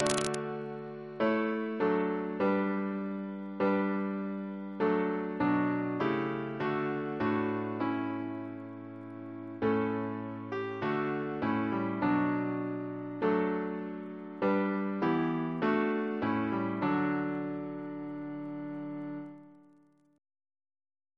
Double chant in D Composer: John Lemon (1754-1814) Reference psalters: ACB: 255